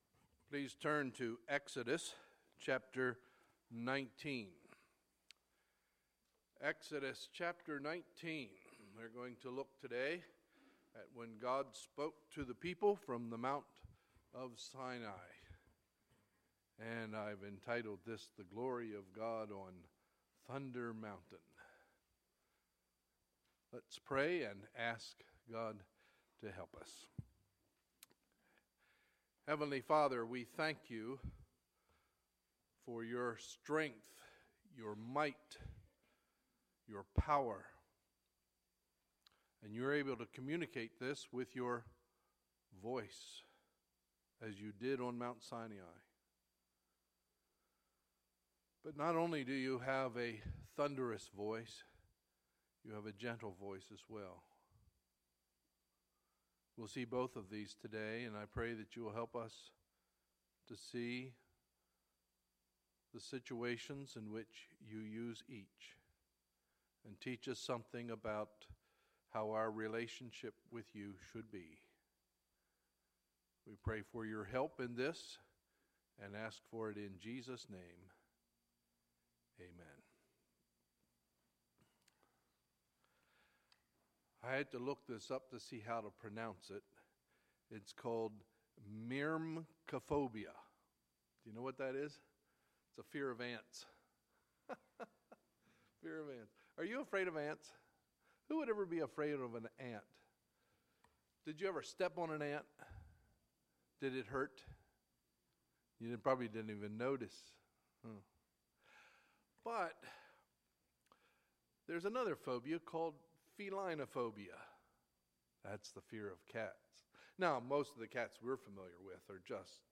Sunday, July 10, 2016 – Sunday Morning Service